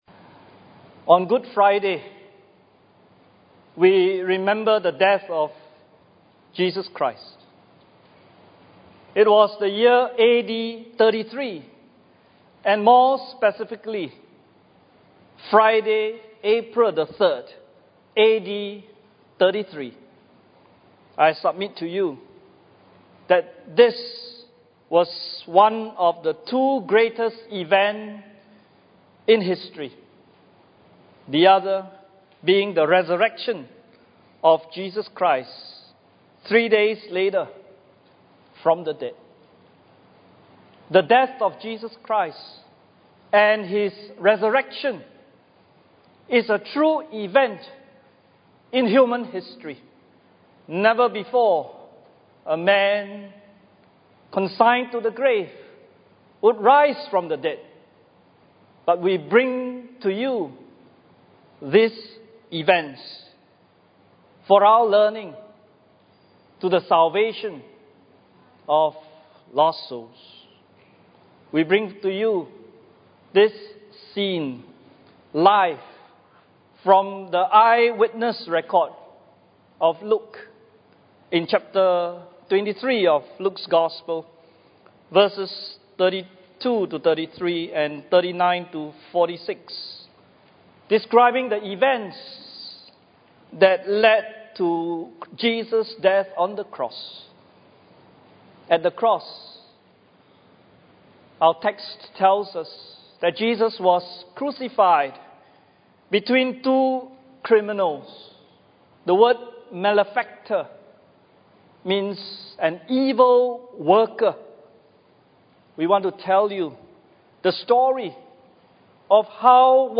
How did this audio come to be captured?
Good Friday Service 2016 – At the Cross